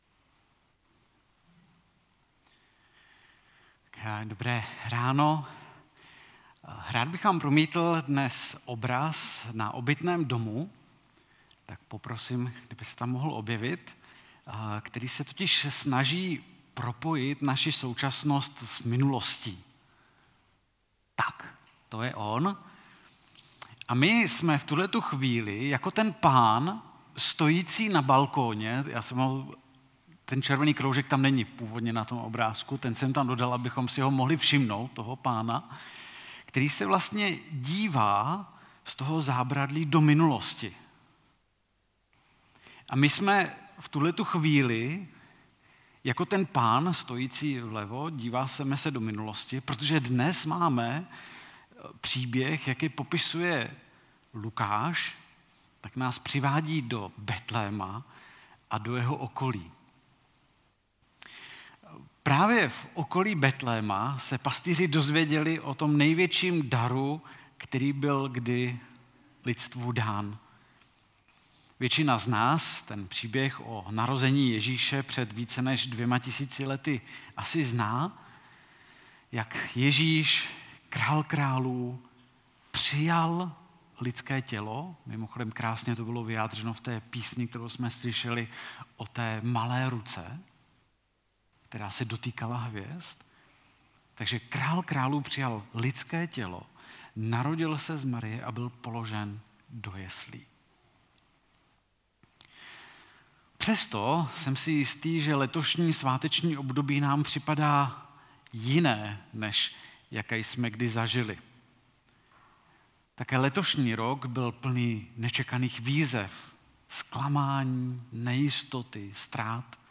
Vánoční bohoslužba